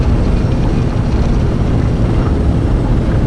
mpb_thrust.wav